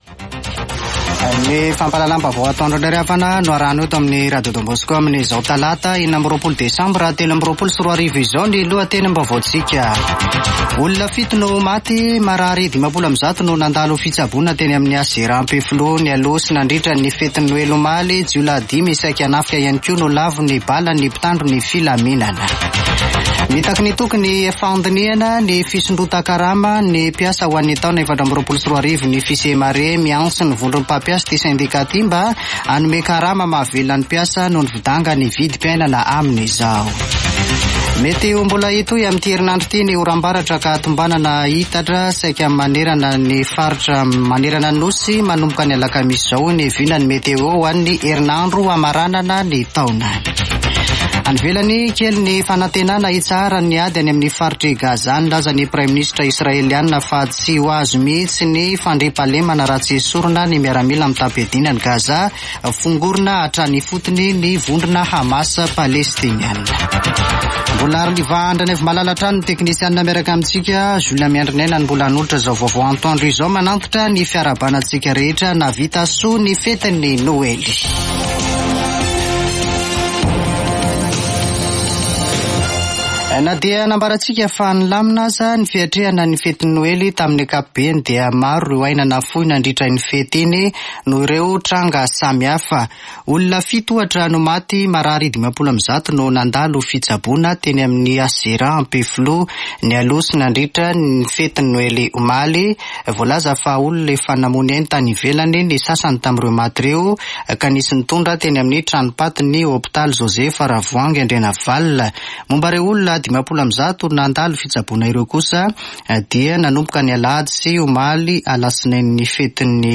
[Vaovao antoandro] Talata 26 desambra 2023